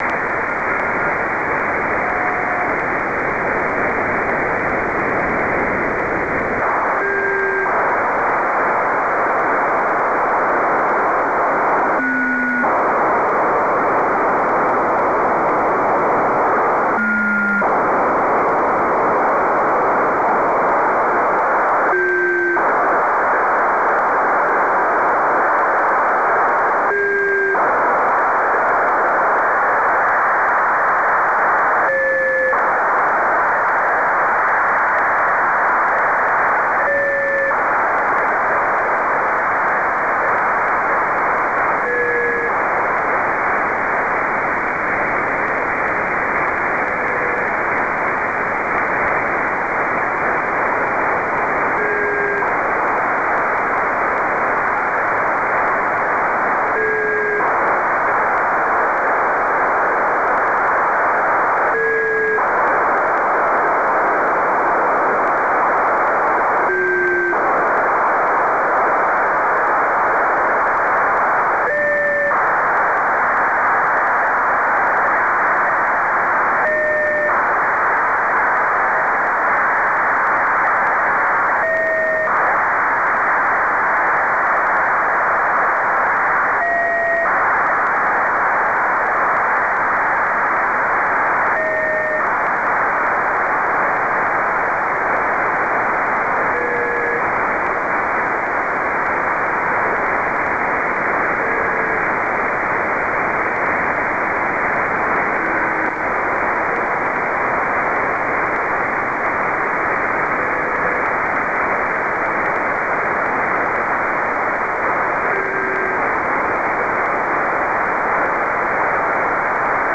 Funcube-1 Satellite 24/Jan/2014 at 15.43 utc  Telemetry RX on 145.935 MHz-- Audio file. (168 seconds /  2.95 Mbyte)